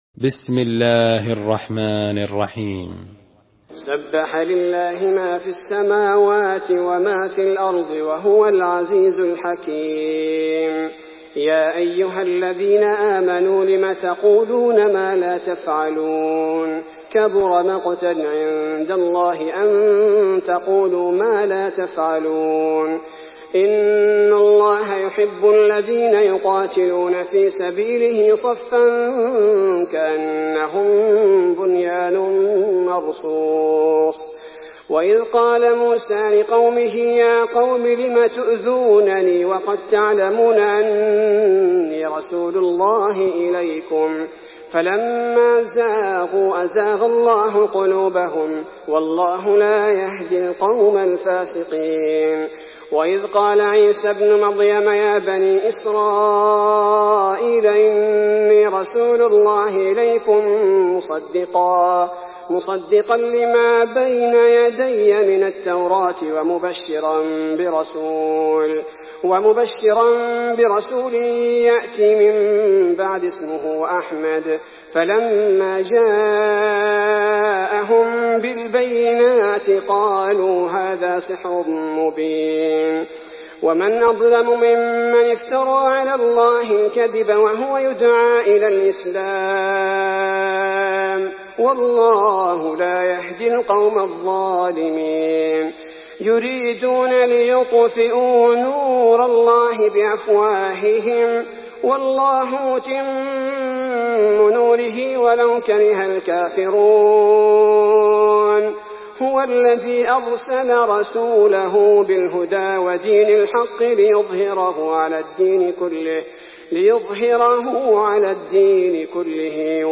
تحميل سورة الصف
مرتل